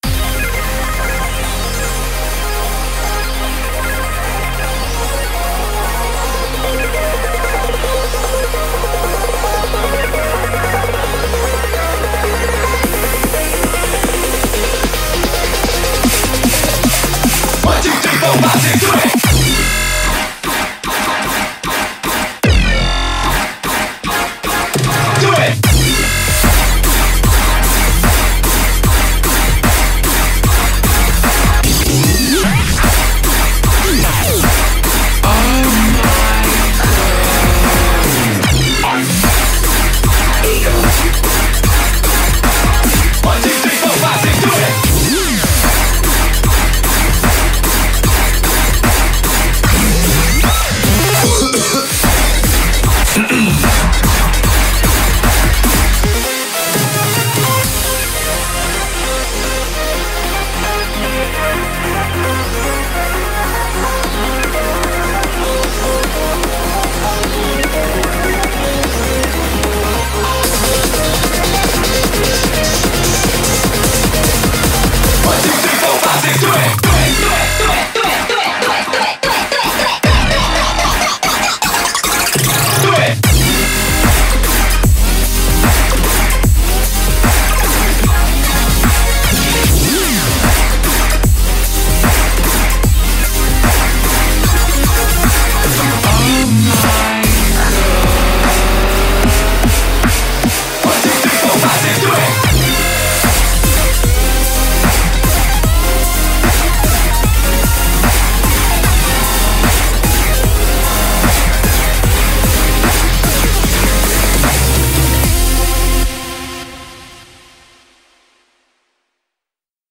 BPM75-750
CommentsMELODIC RIDDIM